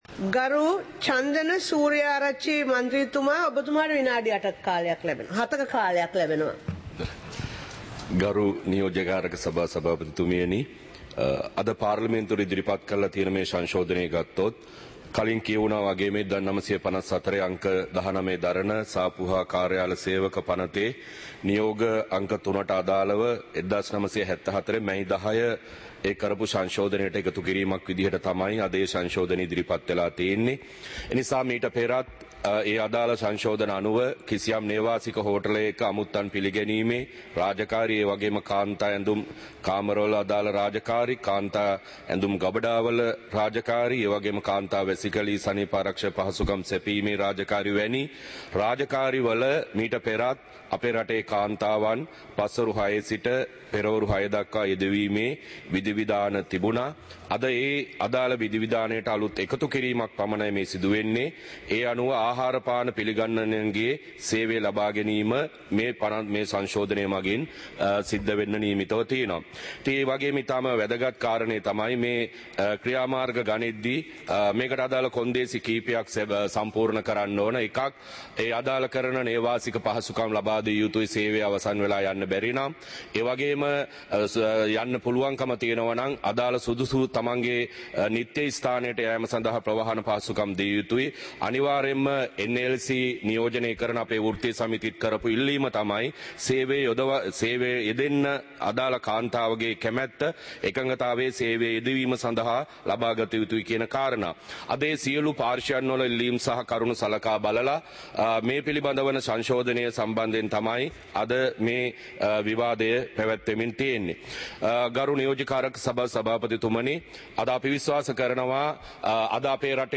இலங்கை பாராளுமன்றம் - சபை நடவடிக்கைமுறை (2026-01-09)